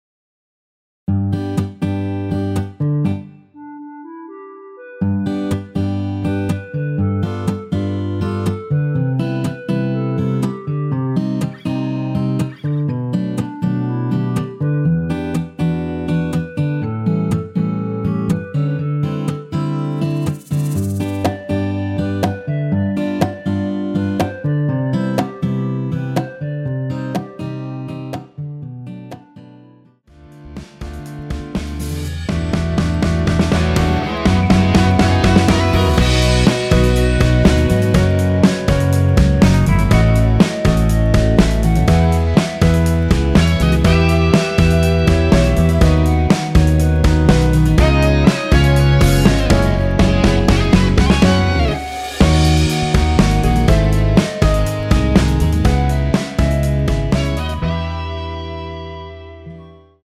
원키 멜로디 포함된 MR입니다.
전주없이 노래가 시작 되는 곡이라 전주 1마디 만들어 놓았습니다.(미리듣기 참조)
앞부분30초, 뒷부분30초씩 편집해서 올려 드리고 있습니다.
중간에 음이 끈어지고 다시 나오는 이유는